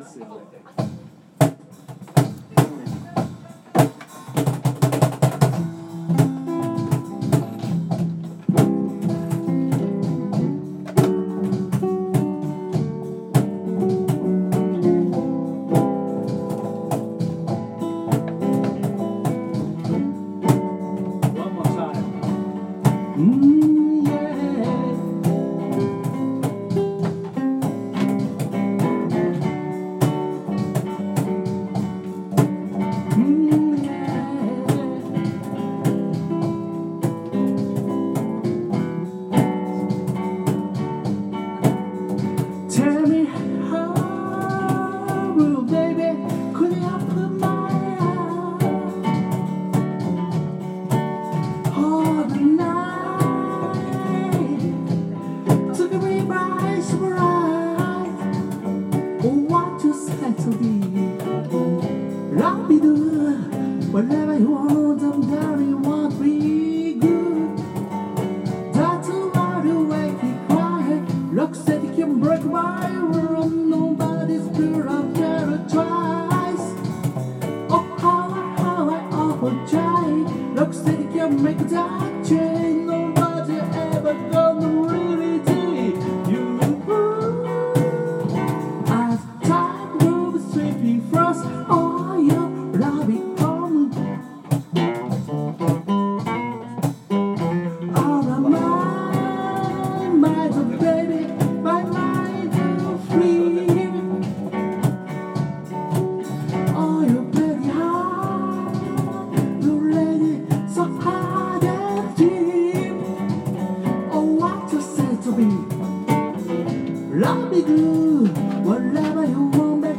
vo gt